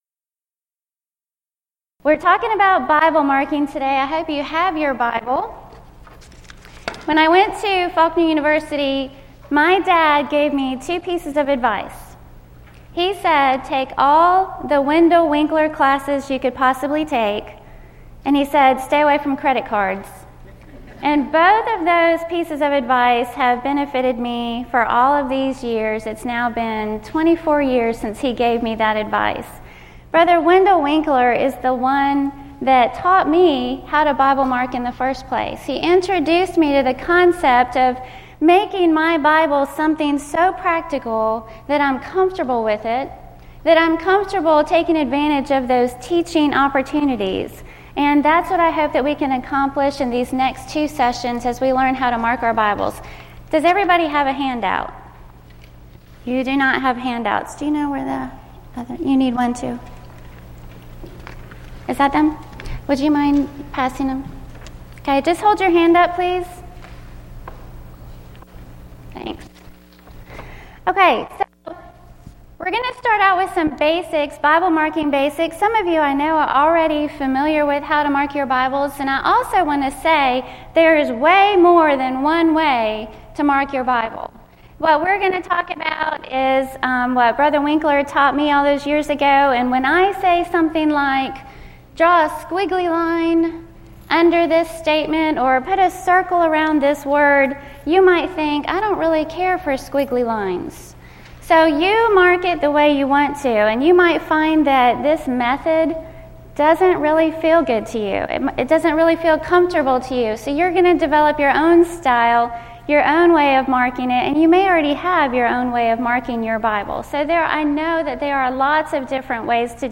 Event: 2014 Focal Point
lecture